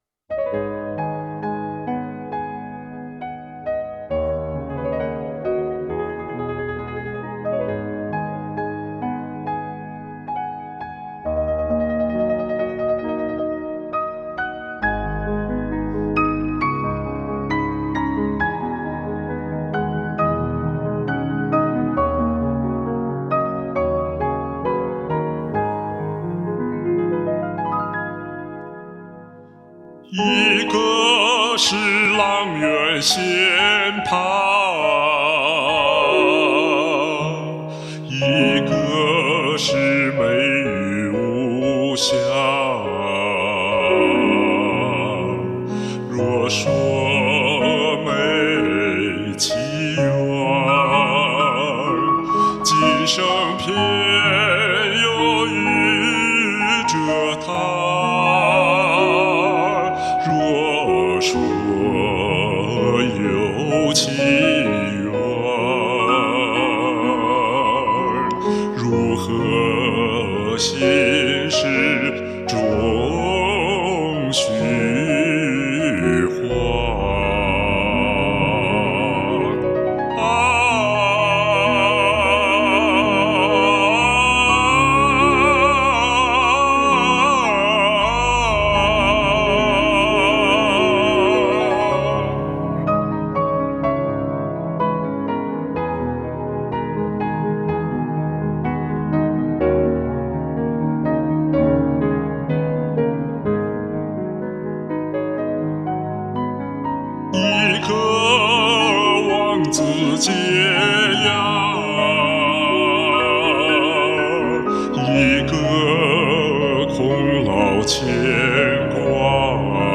中音版男聲，別樣風情，優美動聽！